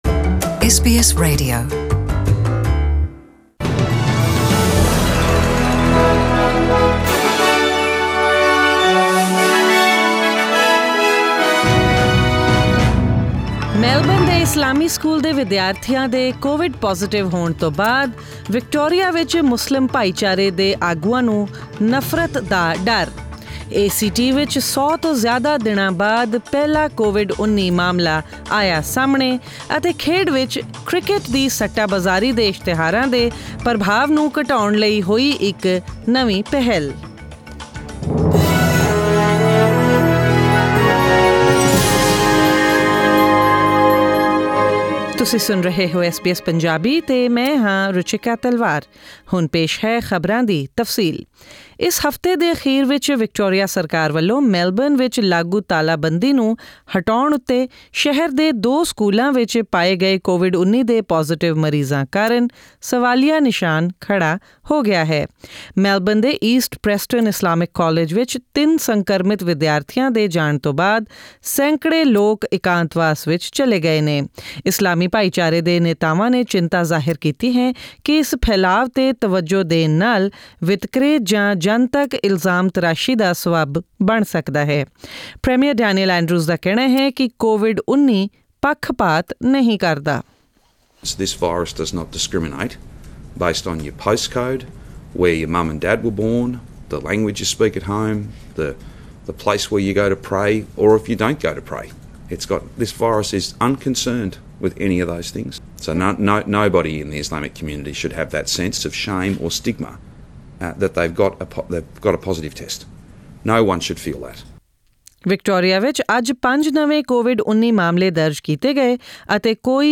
In this bulletin...